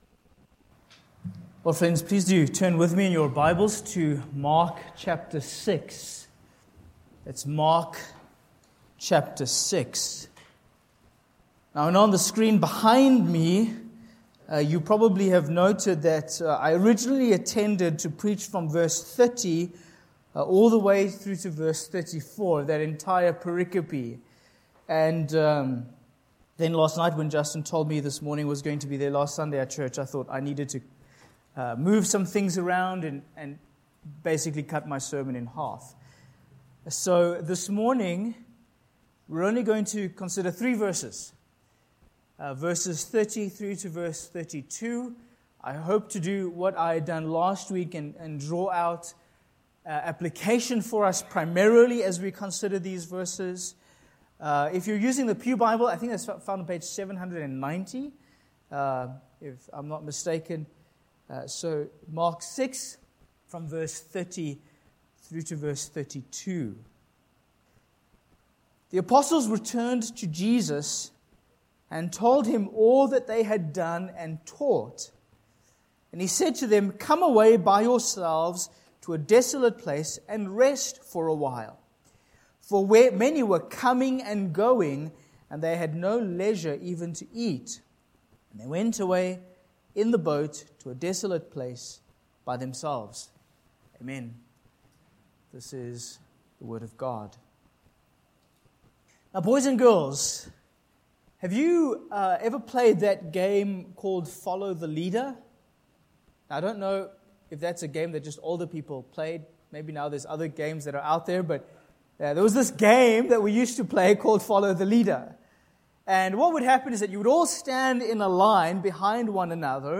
Sermon Points: 1. Accountability to Christ v30